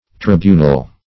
Tribunal \Tri*bu"nal\, n. [L. tribunal, fr. tribunus a tribune